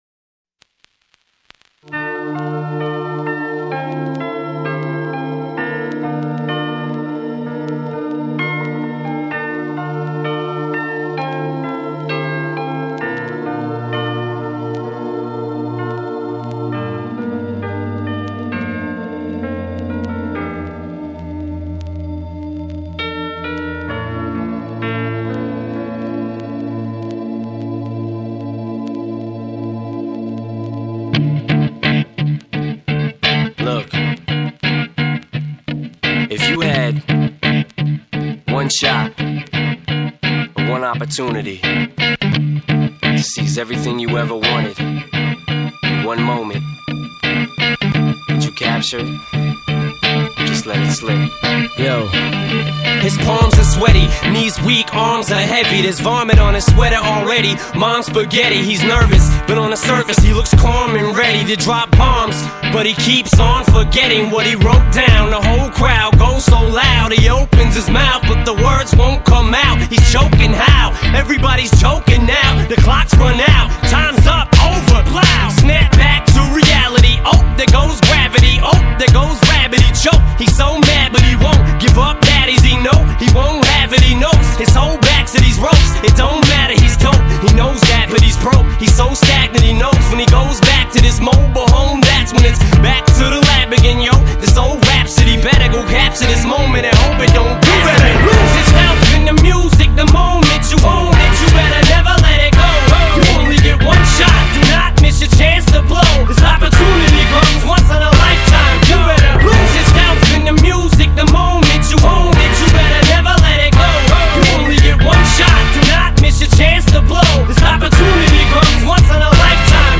Hip Hop, Rap